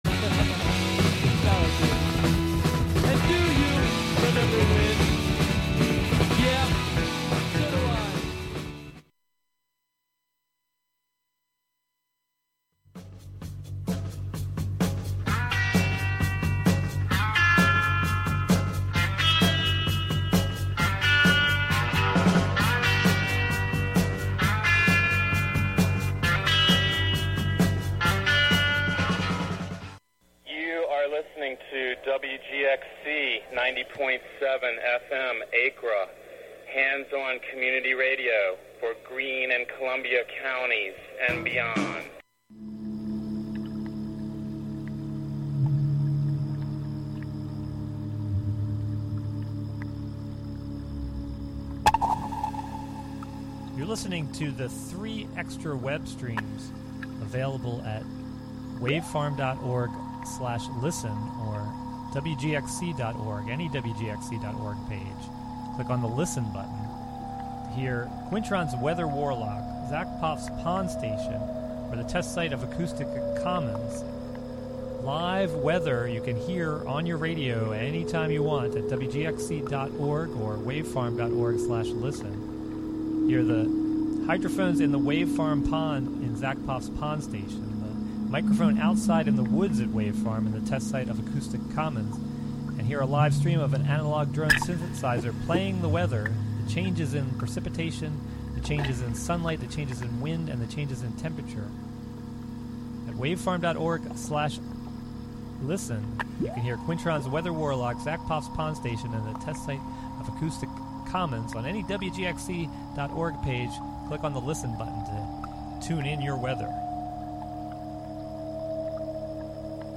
"Home Song #3" is the third in a series of live choral performances by a house in Liverpool, UK
In these hour-long broadcasts ordinarily quiet devices, spaces, and objects within the house are amplified and harmonized into song using sensors and mics then streamed live.
Instead of being silent, a fridge sings of ice and freon, a router sings of packets of data coming and going, a washing machine sings about the love embedded in making mucky things fresh and clean for a new day, and these songs combine to produce a chorus that meets the world through the throat of a live stream over the internet.